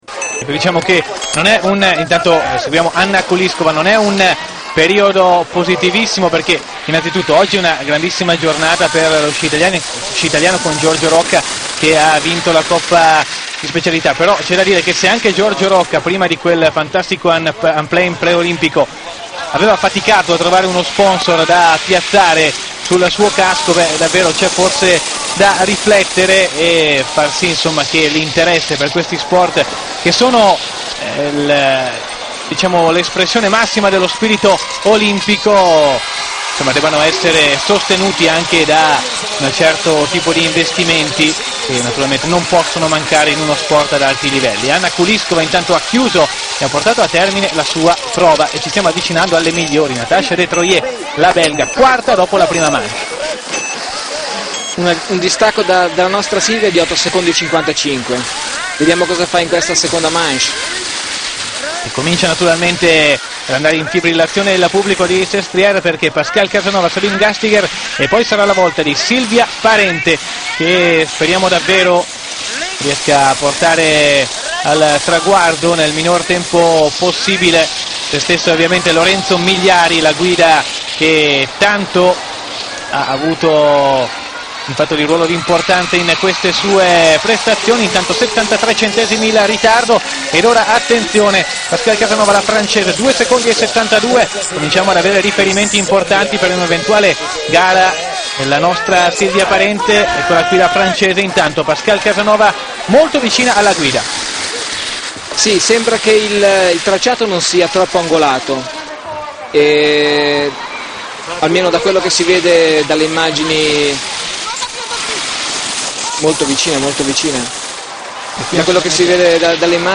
cronacagigante.mp3